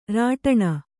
♪ rāṭaṇa